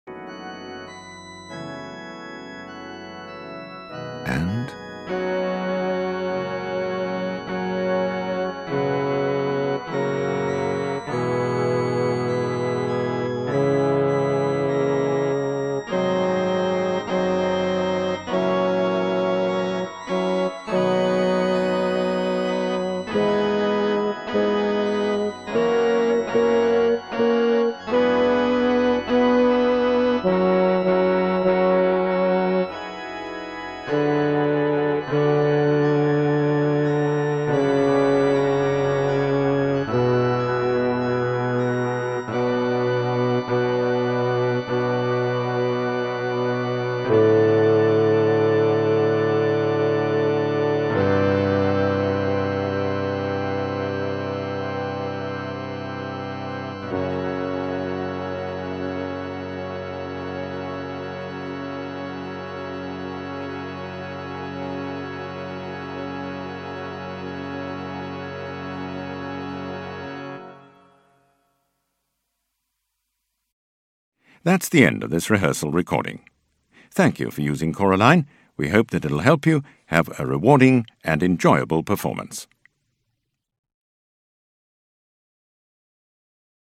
There is no text, just your part.
In Paradisum- begins at square 101 (2nd Bass)